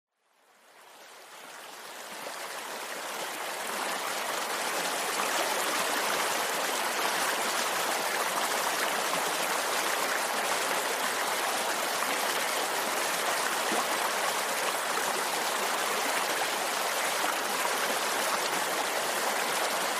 Creek Stream
Creek Stream is a free nature sound effect available for download in MP3 format.
008_creek_stream.mp3